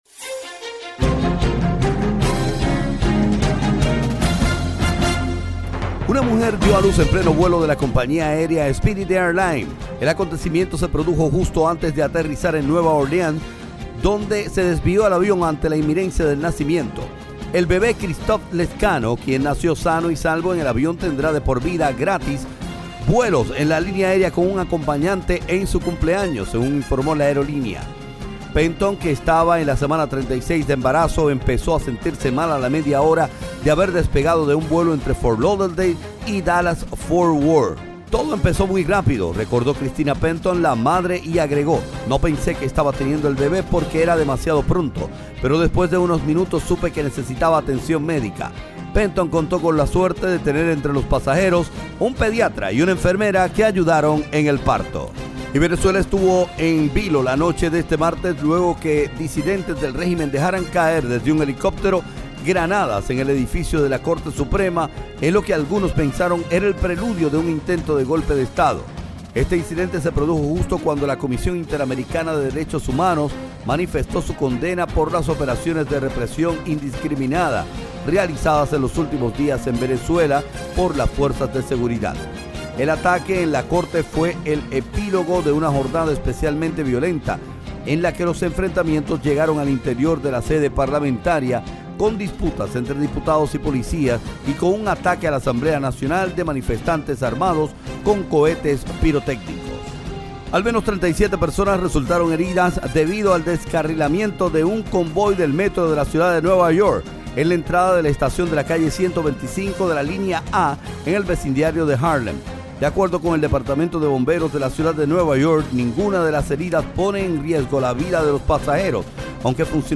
Resumen de Noticias 28 de junio